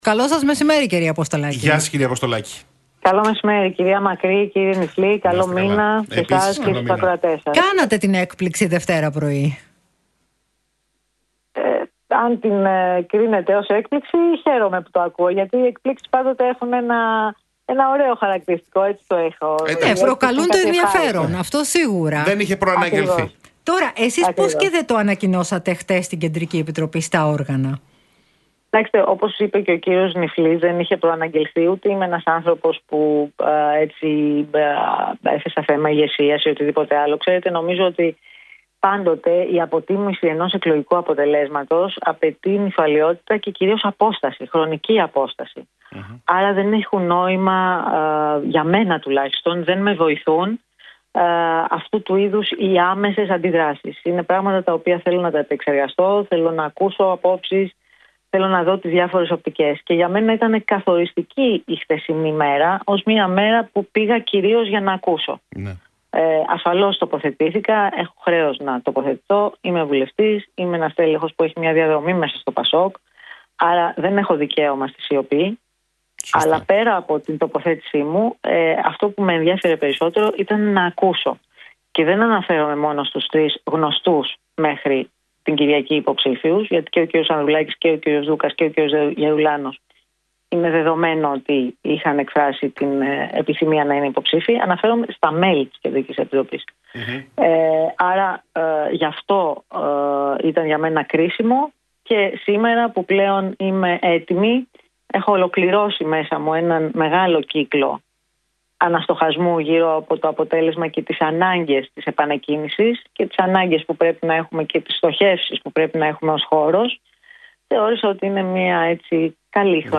Για την απόφασή της να διεκδικήσει την προεδρία του ΠΑΣΟΚ – ΚΙΝΑΛ στις εσωκομματικές κάλπες που θα στηθούν τον Οκτώβριο μίλησε η Μιλένα Αποστολάκη στον Realfm